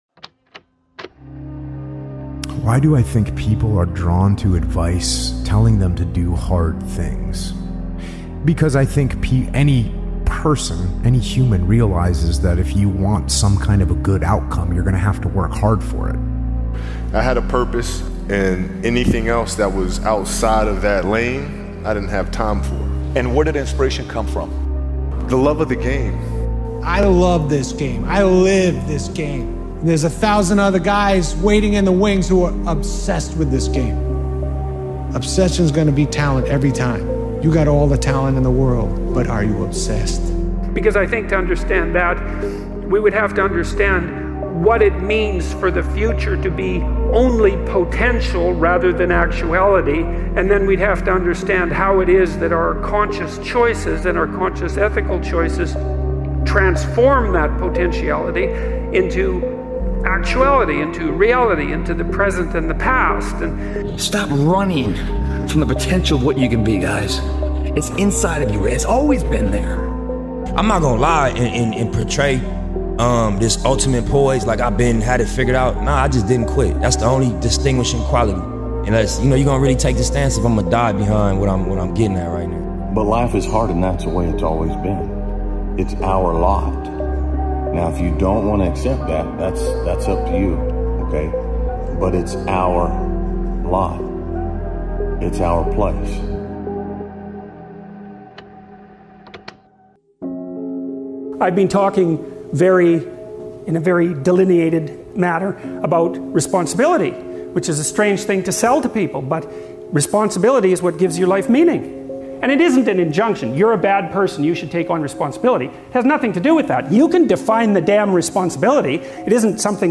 BE THAT GUY | Powerful Motivational Speech Video